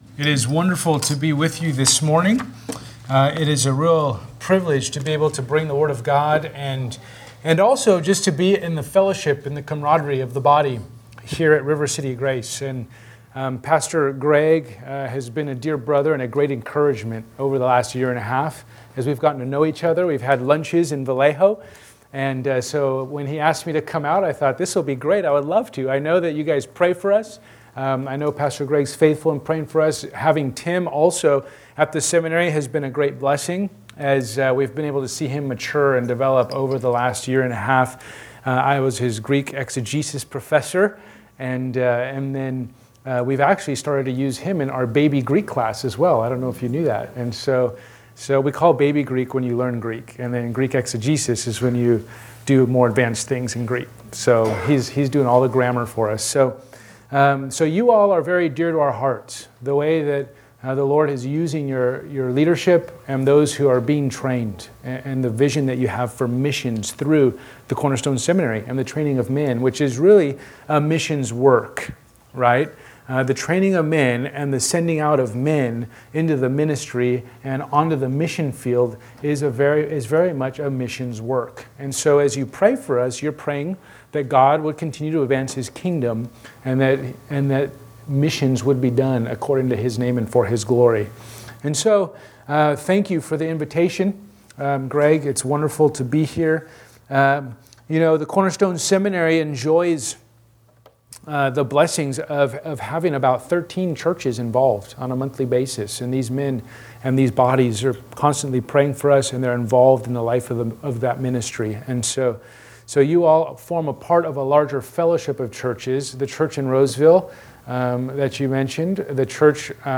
Missions Guest Preacher